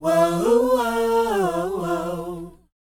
WHOA F C U.wav